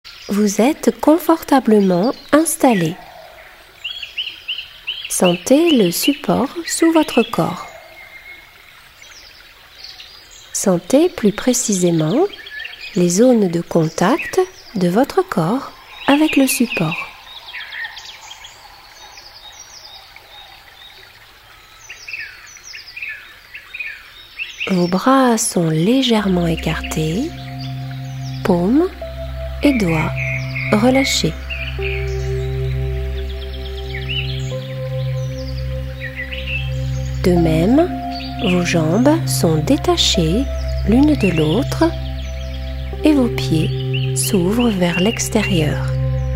Get £2.11 by recommending this book 🛈 Ce livre audio contient quatre séances de relaxation guidée de 25 minutes et deux chants inspirés de mantras indiens invitant à la détente. Décontraction musculaire par auto-suggestion, respirations calmantes et visualisations imaginaires sur fond musical caractérisent cette méthode.
La variété des ambiances offre une pratique très agréable et renouvelée à chaque séance.